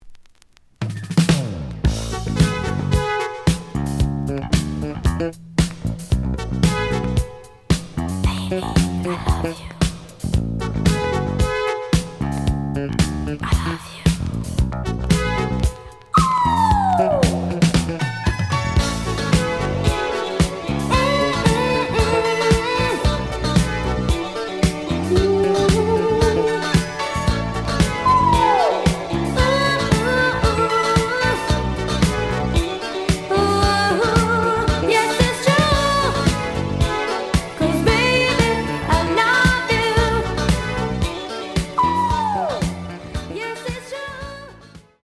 The audio sample is recorded from the actual item.
●Genre: Soul, 80's / 90's Soul
Some click noise on beginning of B side, but almost good.)